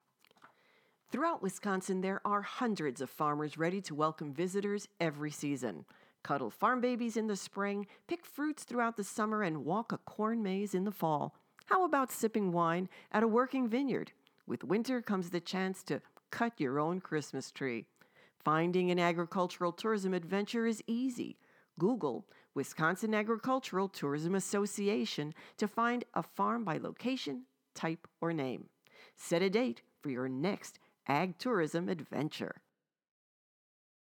Wisconsin Agricultural Tourism – Public Service Announcements (PSAs)